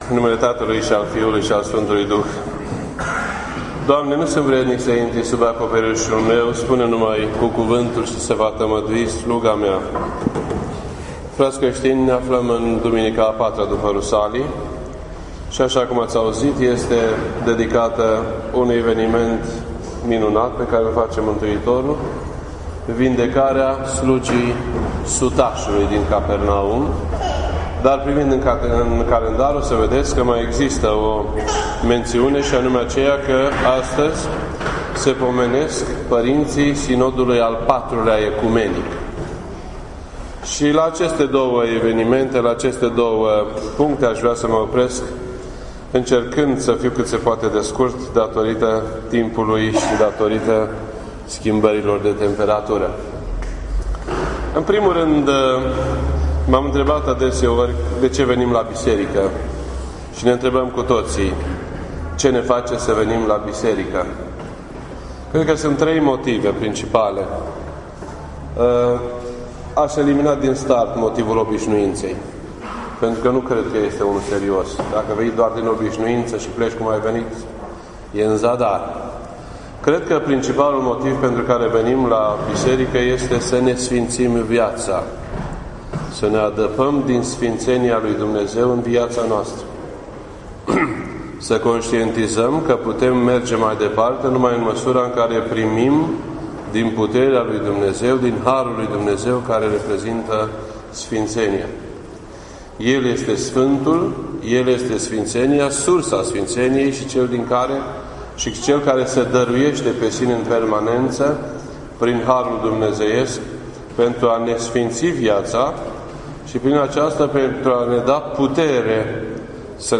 This entry was posted on Sunday, July 17th, 2016 at 10:36 AM and is filed under Predici ortodoxe in format audio.